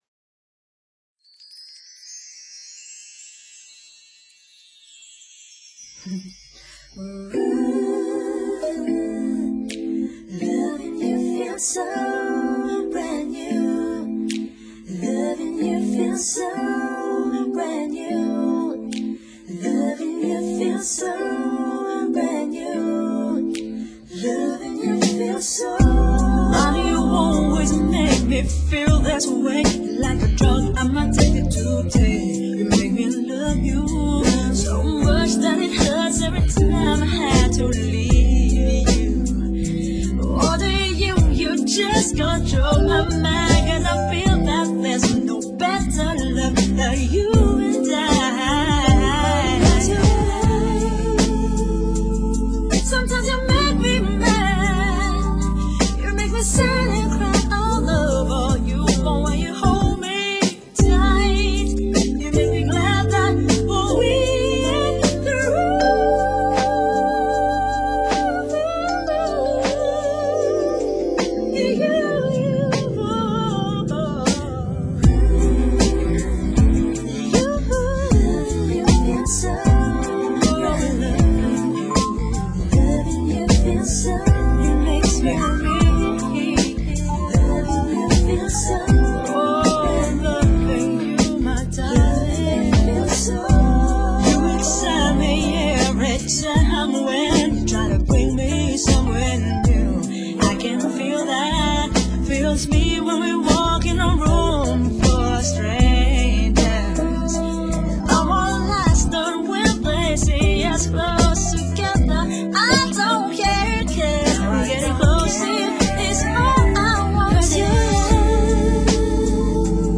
整張專輯都是以輕快歌曲爲主，只可惜是音质差了些